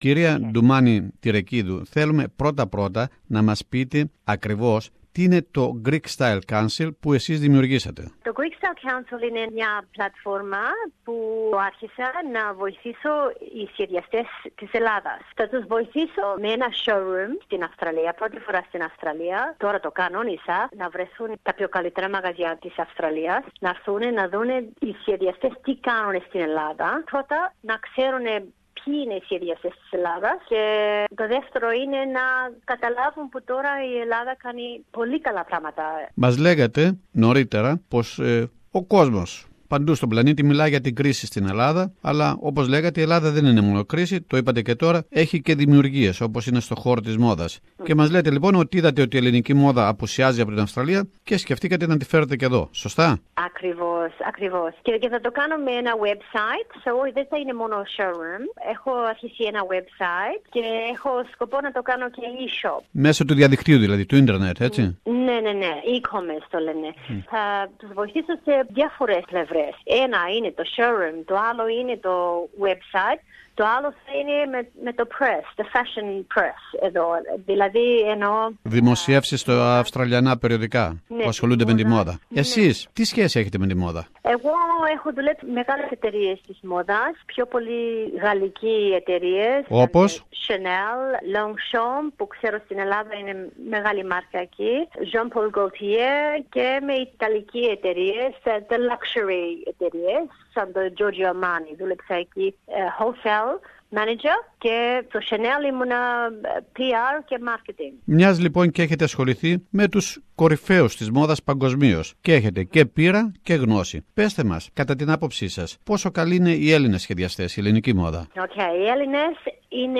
συνέντευξη